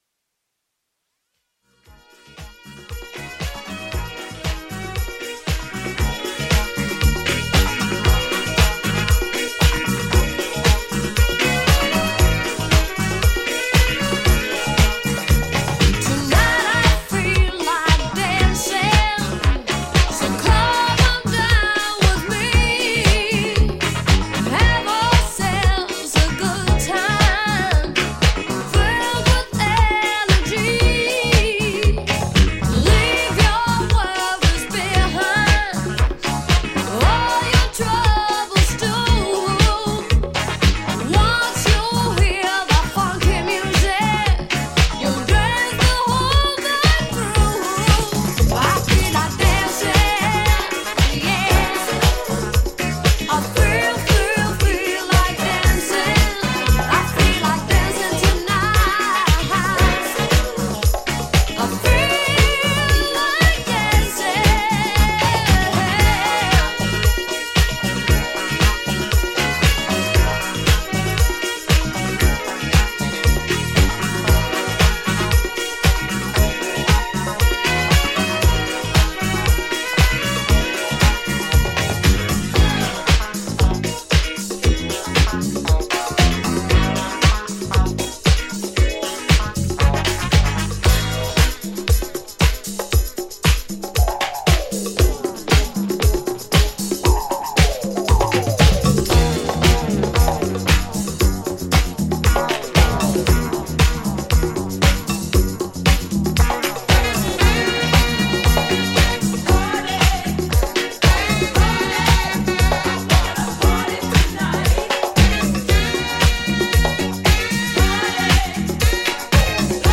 ジャンル(スタイル) DISCO / SOUL /FUNK